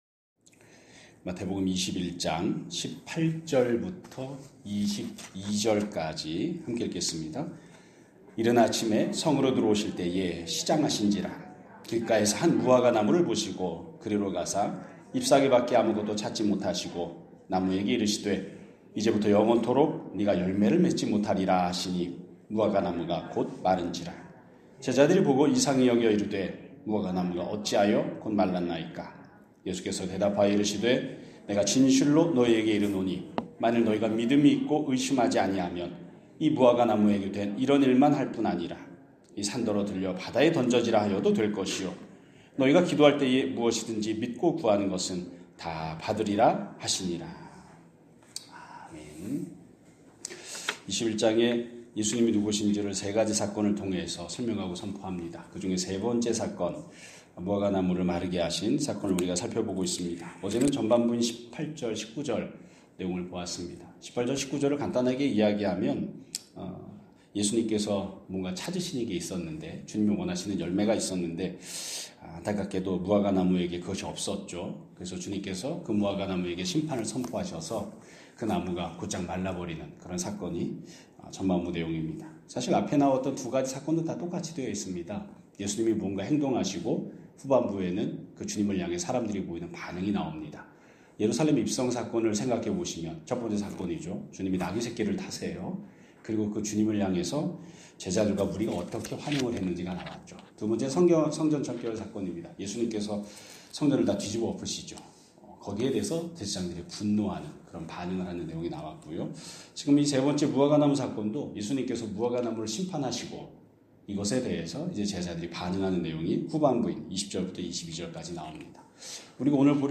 2026년 1월 28일 (수요일) <아침예배> 설교입니다.